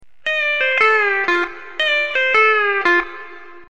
Zumsteel single-neck 12 string steel guitar
Here are a few very easy licks to get you started playing the honky tonk sound.
Honky Tonk #1.  In the key of F, the first string is raised 1/2 step indicated